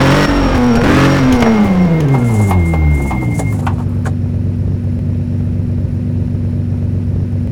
throttle_off.wav